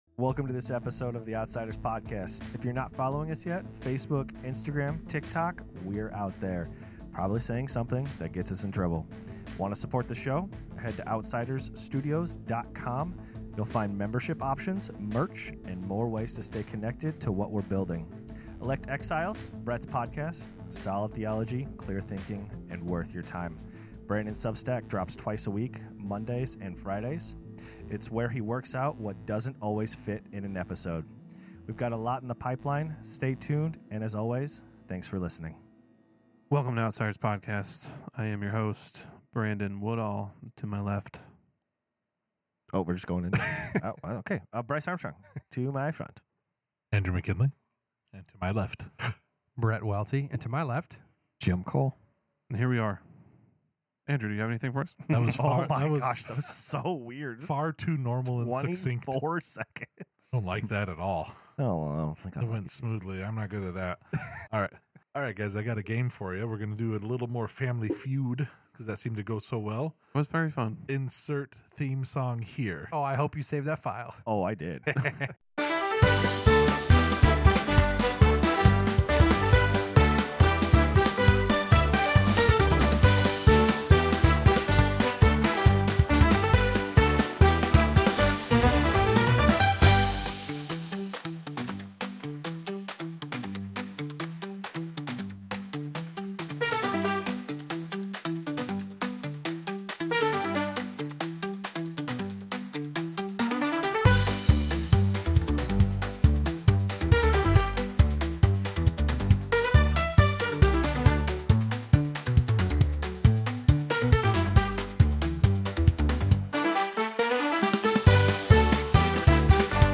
Conversations about The Gospel of Christ. We talk about how to be outside the culture of our day with a biblical point of view.